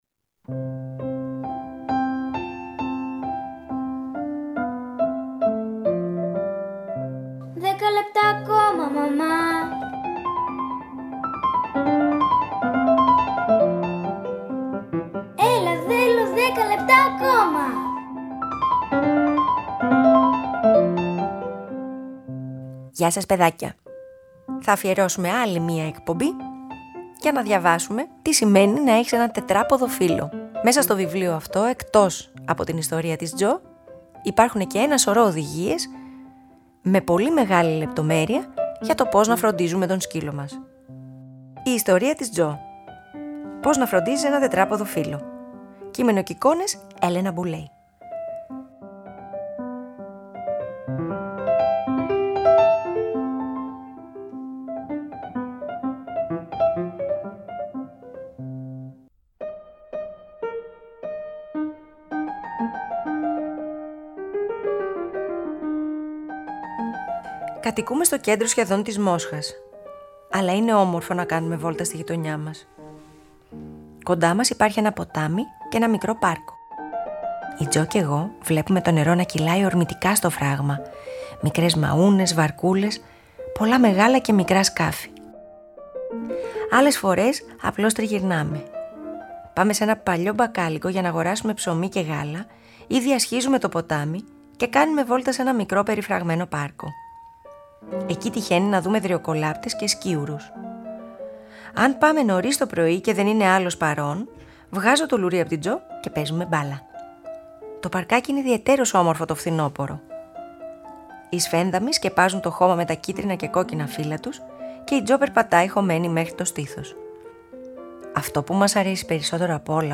Ένα βιβλίο αφιερωμένο στο πώς να φροντίζουμε τους τετράποδους φίλους μας. Αφήγηση-Μουσικές επιλογές